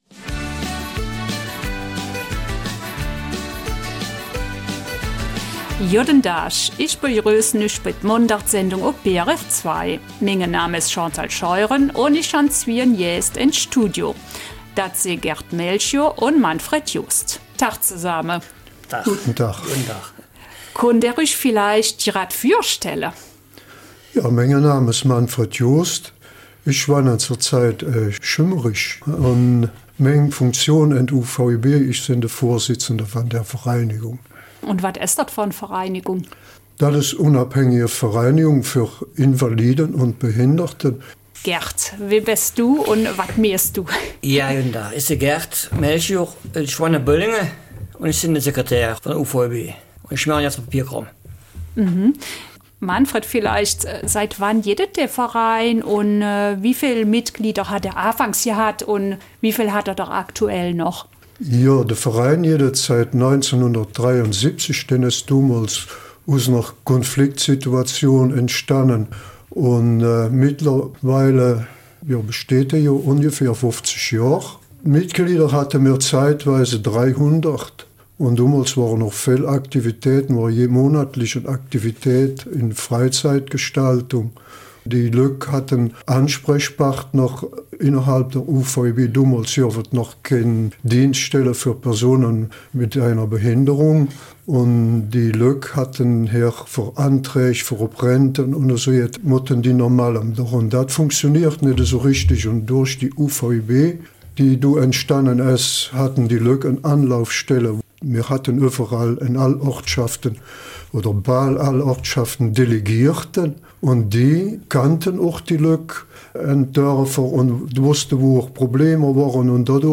Eifeler Mundart: Behinderten- und Invalidenvereinigung 09.